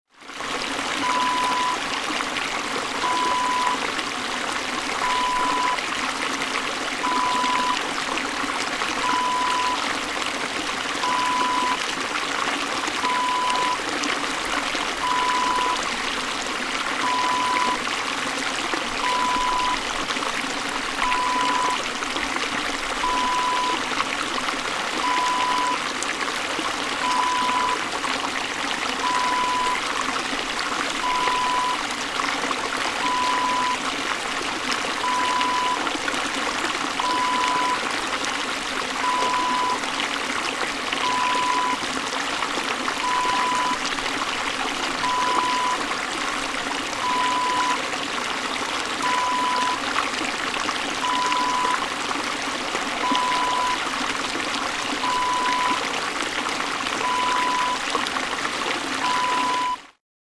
Water Stream (Brook) Wav Sound Effect #2
Description: The sound of a small water stream (brook) in the forest
Properties: 48.000 kHz 16-bit Stereo
A beep sound is embedded in the audio preview file but it is not present in the high resolution downloadable wav file.
Keywords: river, stream, streaming, brook, creek, water, running, rushing, mountain, nature, waterfall, fall, trickle, relaxation
water-stream-small-preview-2.mp3